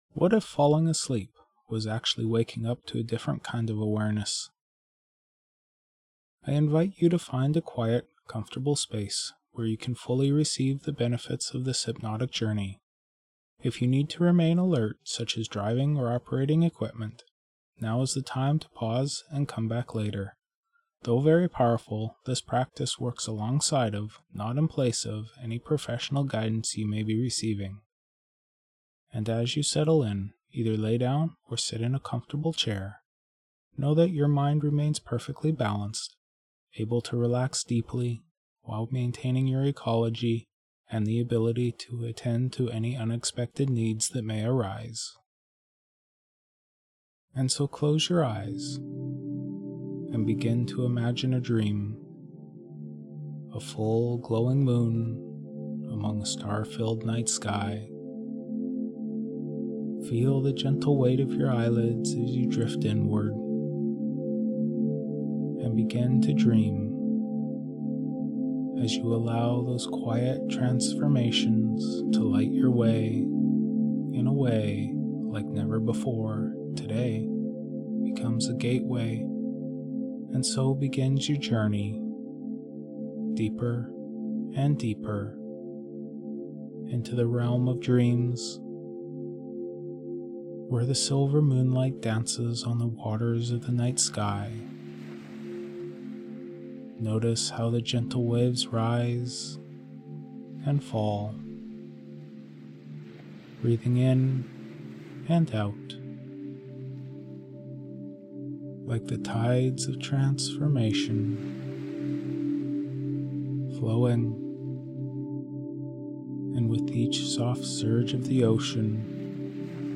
A Guided Hypnotic Journey to Reclaim Restorative Sleep
Using Ericksonian techniques, guided visualization, and hypnotic storytelling, this audio gently reshapes internal resistance into alignment.